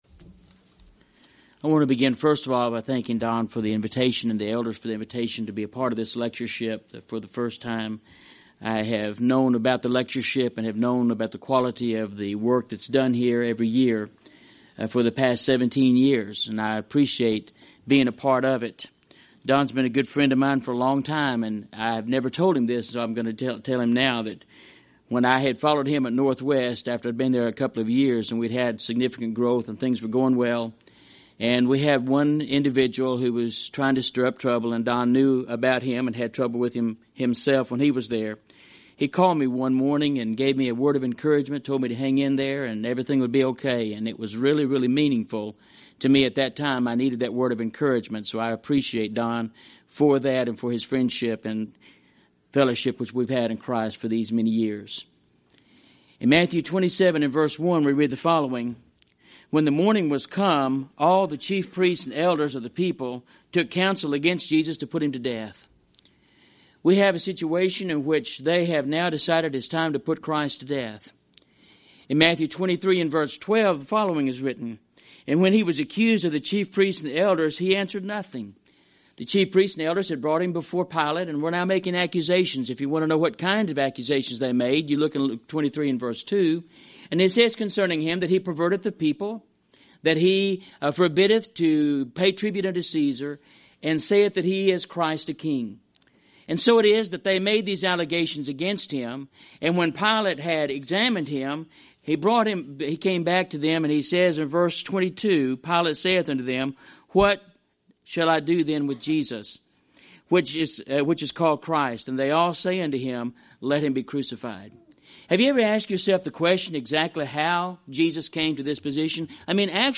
Event: 2003 Annual Shenandoah Lectures Theme/Title: Great Questions in the Bible